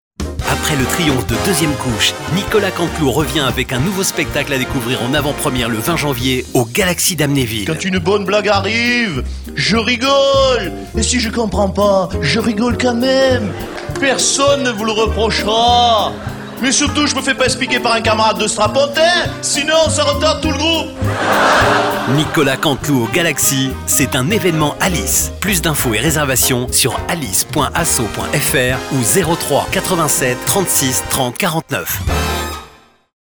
Spots Radio / RFM - DIRECTFM - VIRGINRADIO